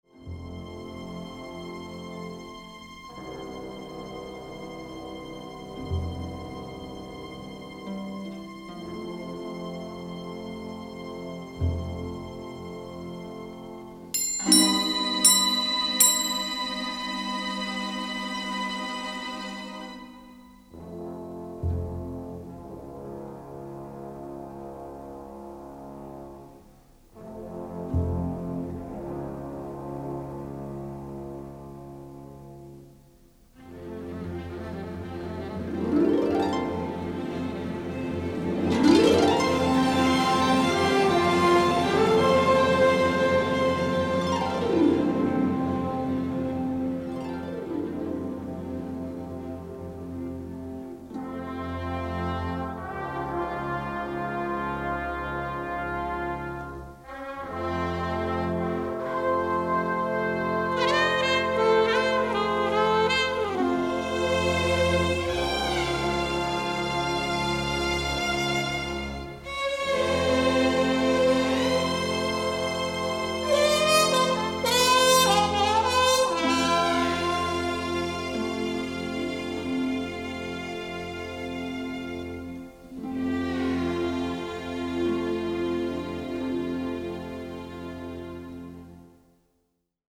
orchestral score